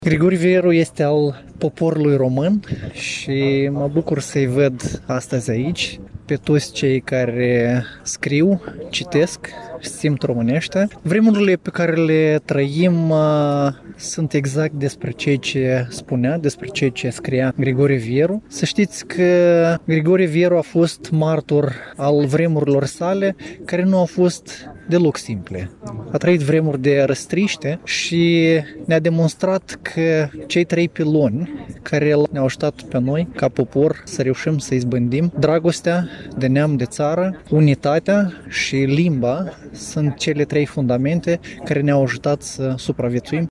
La statuia poetului Grigore Vieru din Parcul Copou din Iași s-au marcat, astăzi, 90 de ani de la nașterea scriitorului.
Cu acest prilej, a fost oficiat un ceremonial militar și religios.